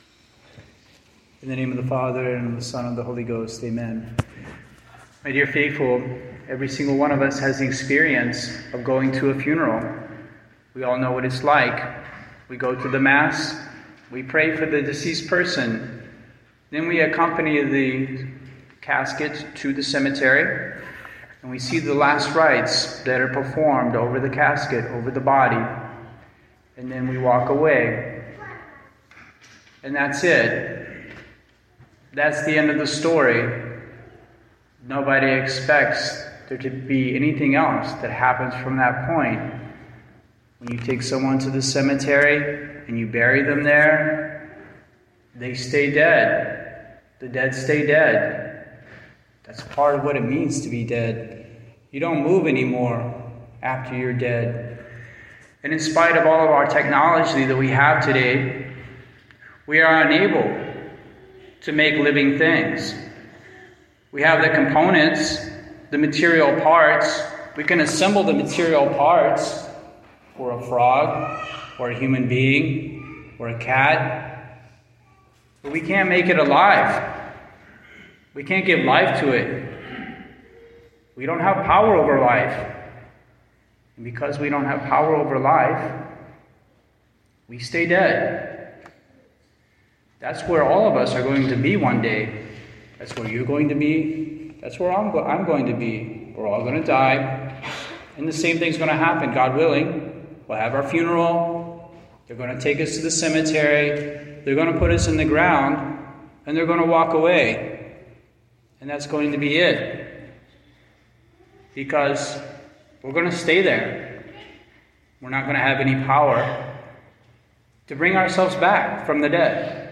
Catholic Politics, Sermon